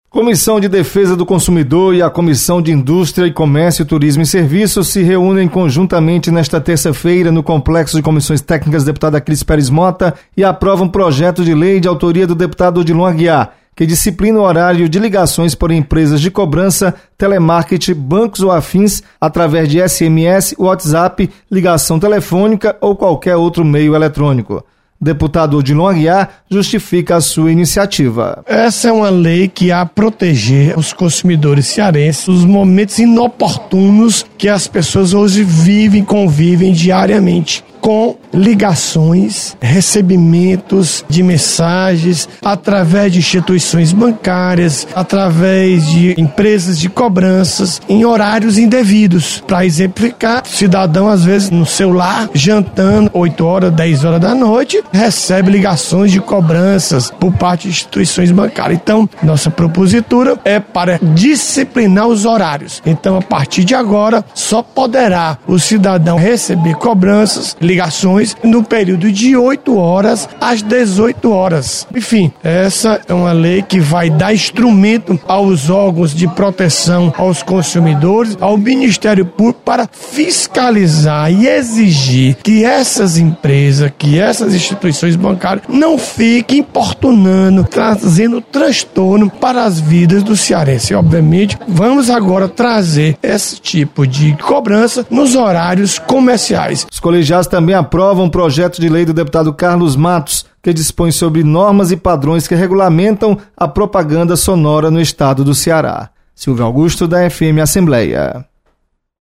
Comissão de Defesa do Consumidor aprova projeto que disciplina envio de mensagens por telemarketing. Repórter